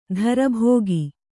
♪ dhara bhōgi